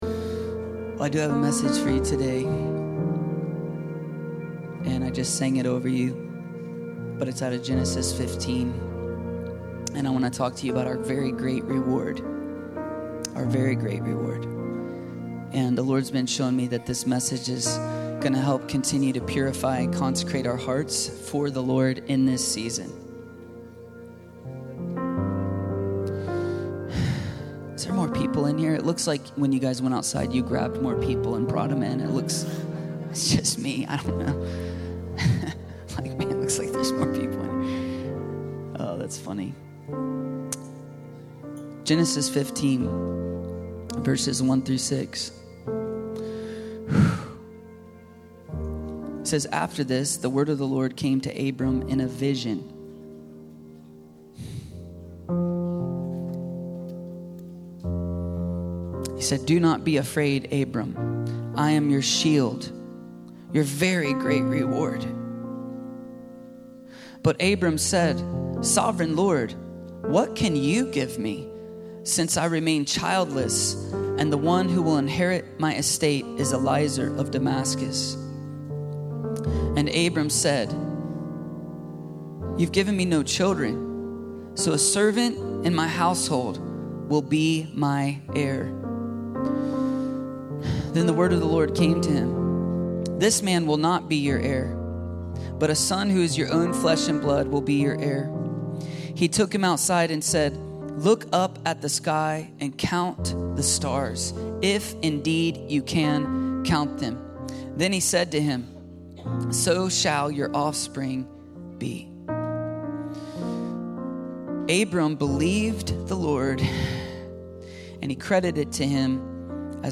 Very Great Reward - Very Great Reward ~ Free People Church: AUDIO Sermons Podcast